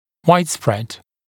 [‘waɪdspred][‘уайдспрэд]широко распространённый